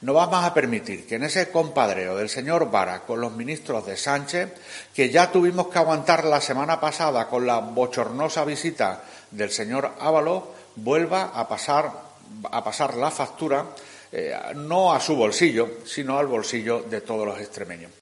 José Antonio Monago en rueda de prensa. Sonido: COPE